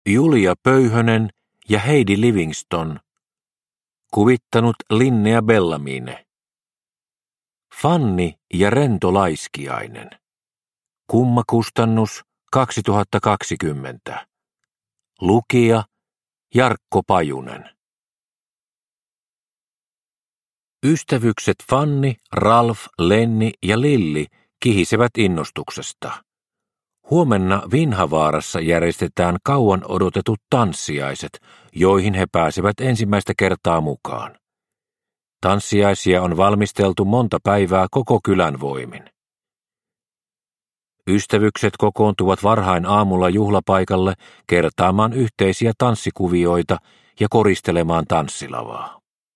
Fanni ja rento laiskiainen – Ljudbok – Laddas ner